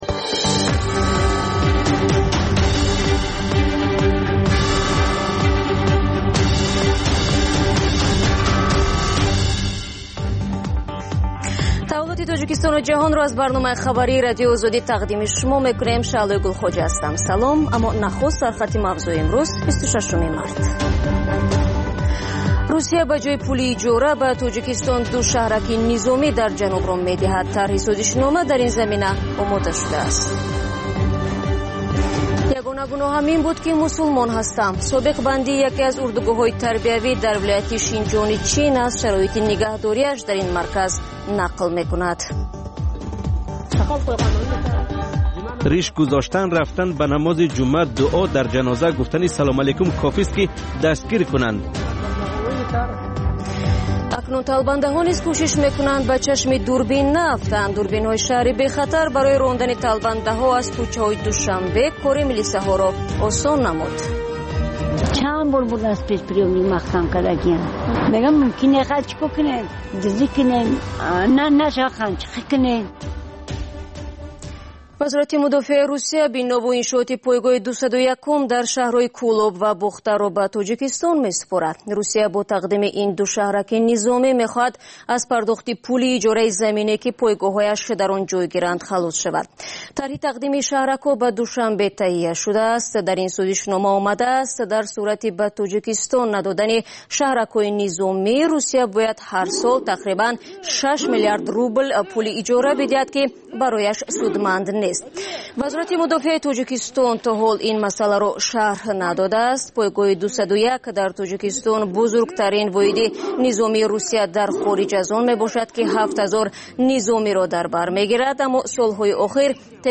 Тозатарин ахбор ва гузоришҳои марбут ба Тоҷикистон, минтақа ва ҷаҳон дар маҷаллаи бомдодии Радиои Озодӣ